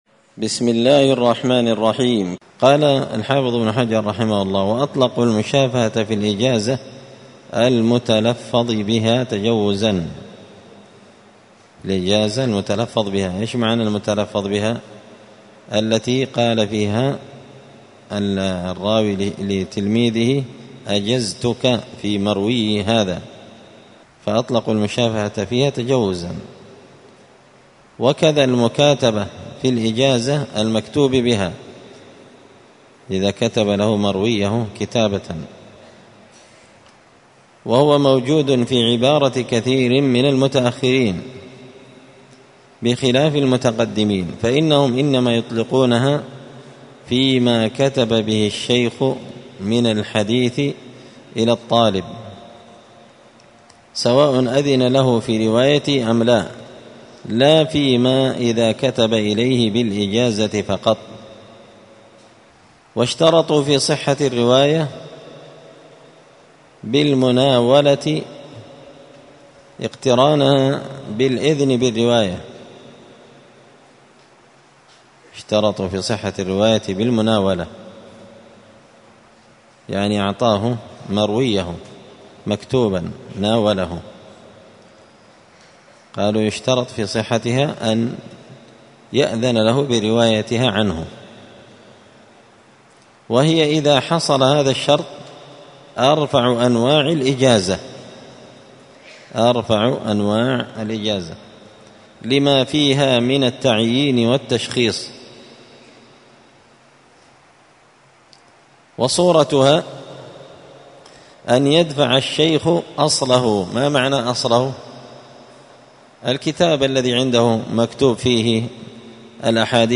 تعليق وتدريس الشيخ الفاضل: